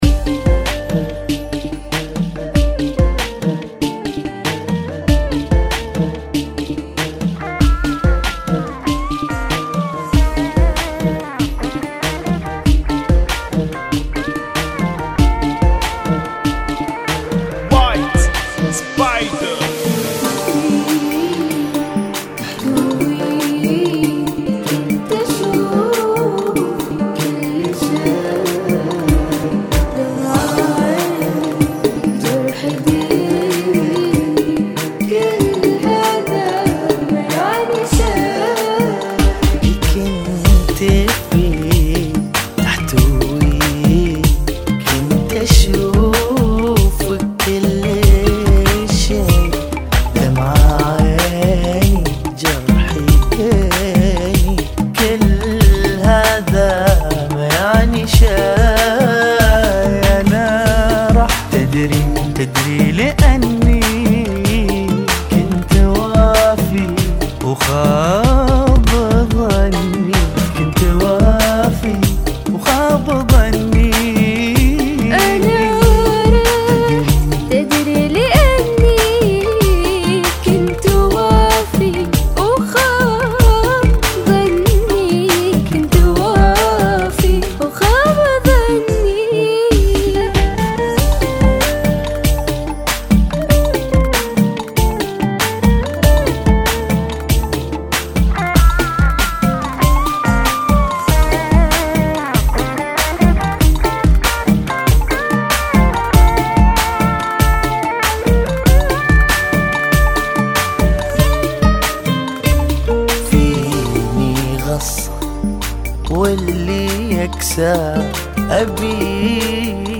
[ 95 Bpm ]